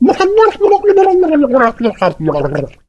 otis_start_vo_06.ogg